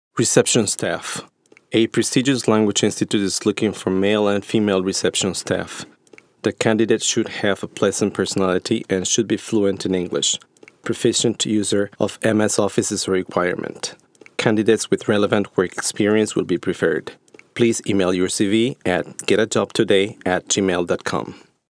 3.a. Listening to the advertisement